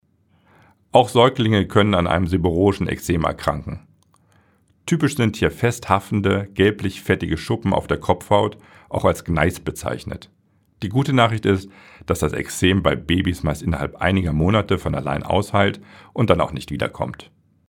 O-Töne17.04.2024